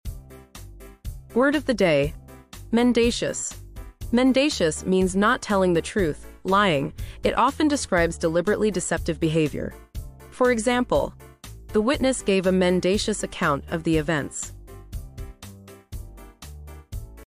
Today's Word: Mendacious 🎙 Pronunciation: /menˈdeɪ.ʃəs/